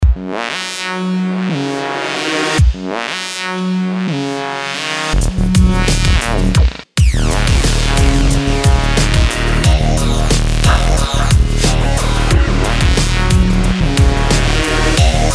Жужжащий звук
Что то я не пойму с чего начать, это с Nord наверное звук, раньше накручивал такое, а сейчас забыл...